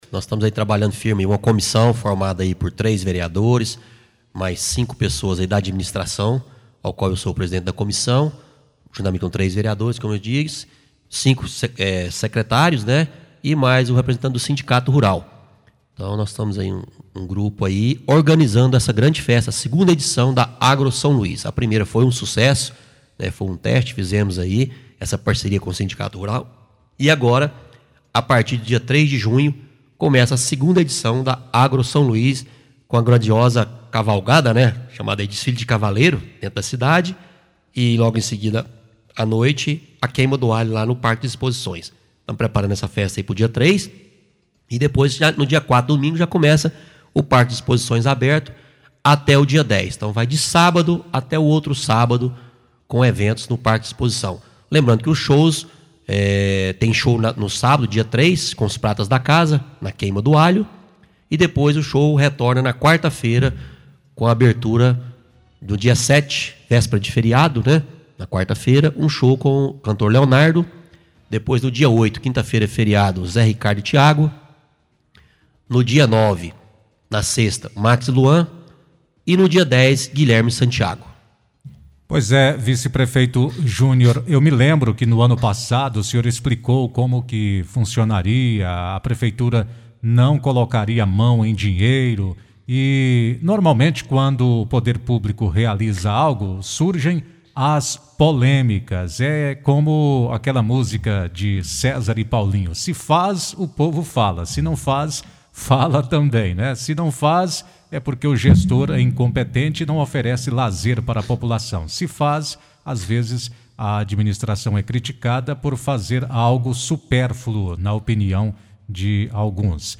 Fique por dentro de tudo que envolve a festa, inclusive acerca da locação de espaço para comercialização de bebidas e comidas, nas explicações do vice – prefeito, Júnior da Receita.